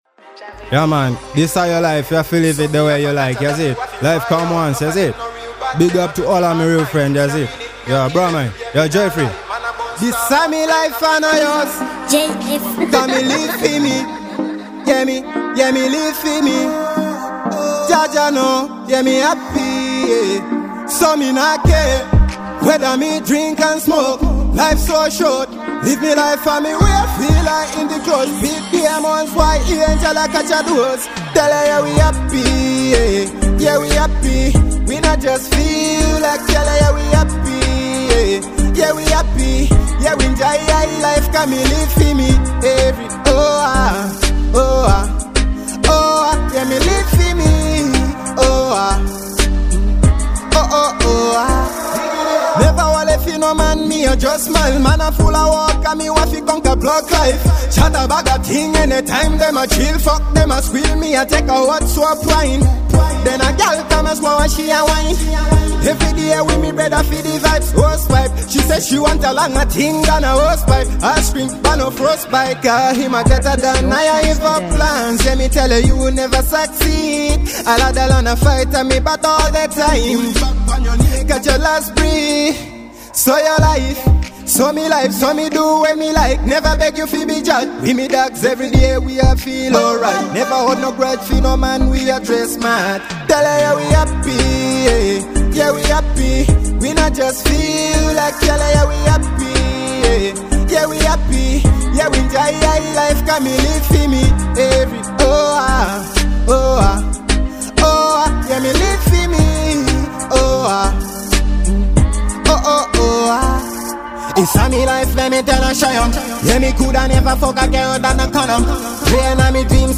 2. Dancehall